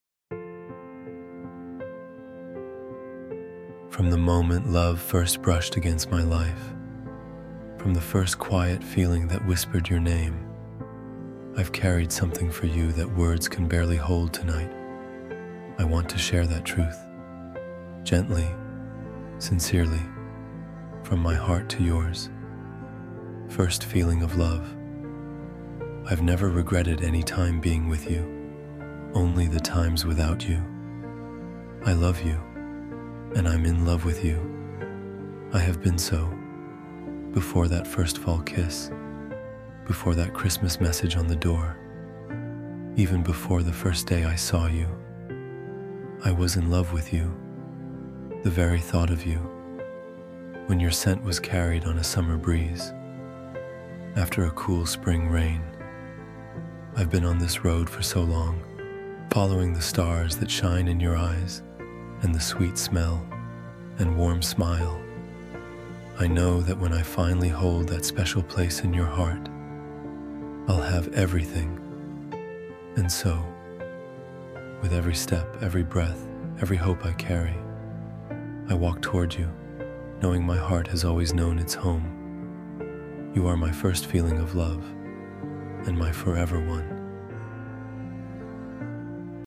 First-Feeling-of-Love-–-Romantic-Spoken-Word-Poem.mp3